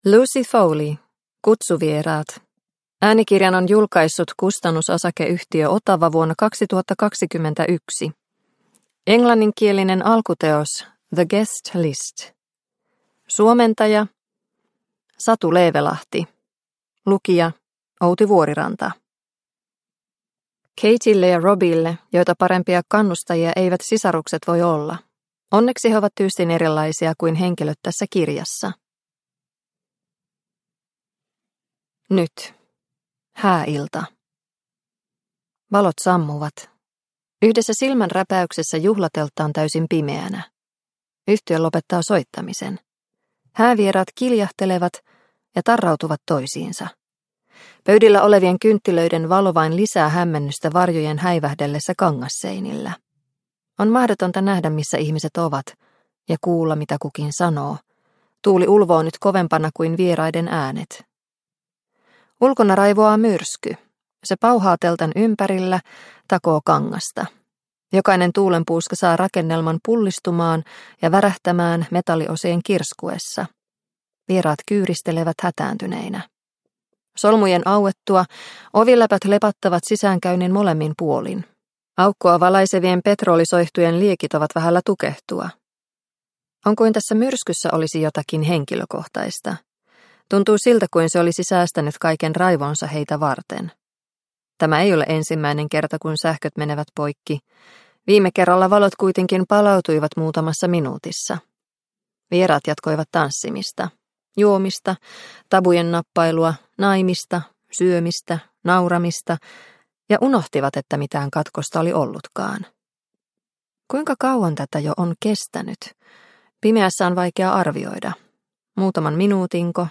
Kutsuvieraat – Ljudbok – Laddas ner